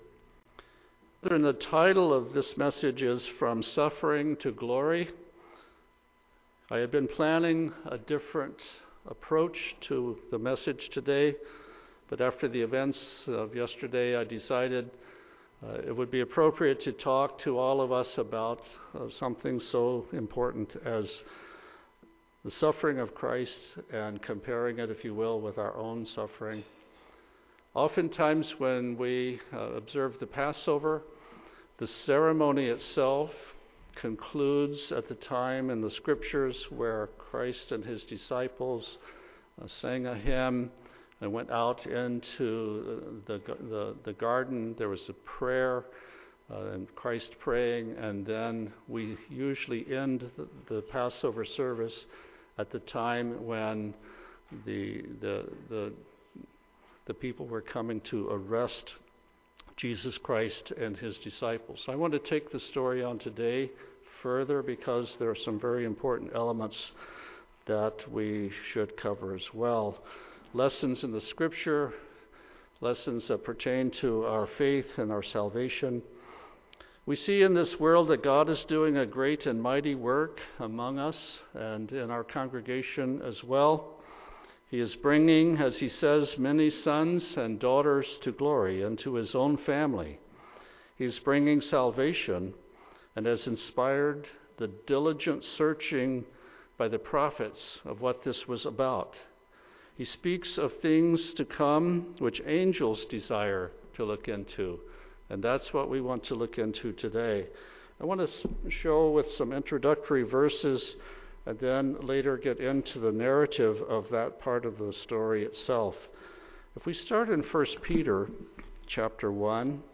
In this message we will read the inspired narrative of these integral elements of the gospel from a "Harmony of the Gospels," combining details from each of the four Scriptural accounts. To keep the story flow intact, the reading is without reference to each of the chapters and verses.